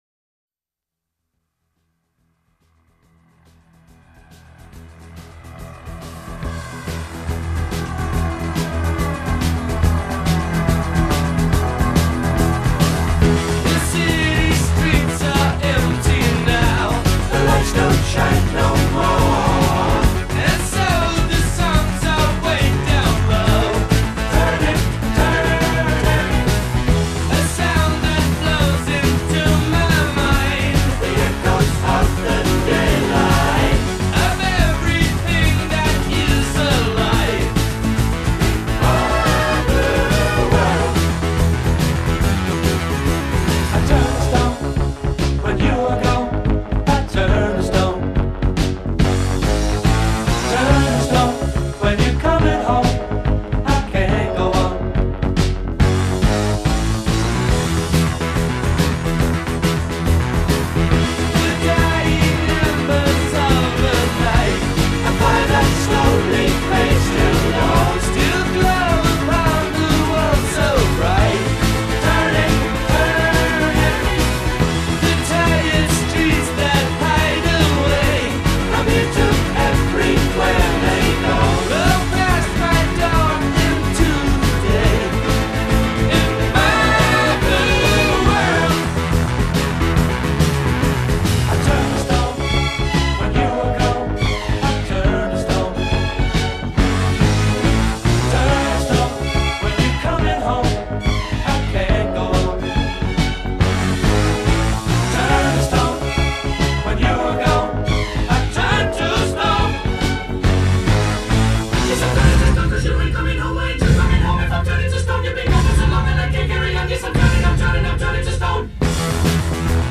Pop Rock, Orchestral Rock